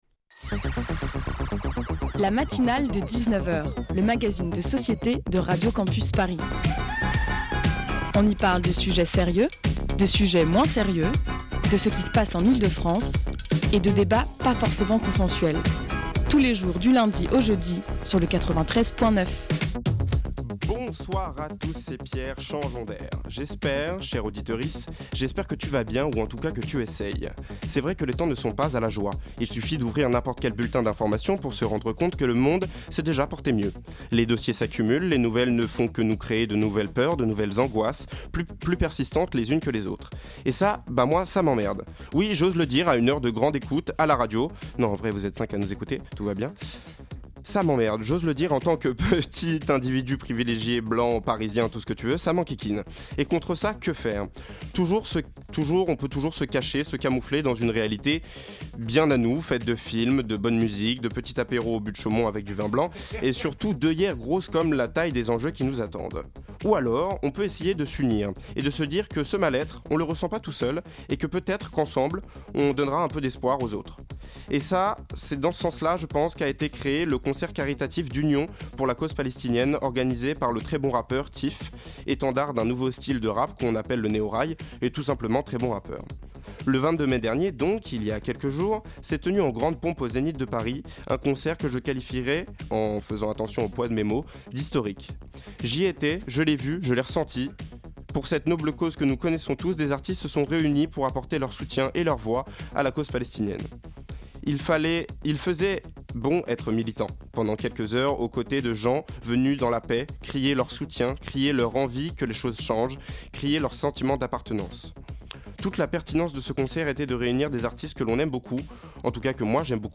Une heure d'émission agrémentée de trois chroniques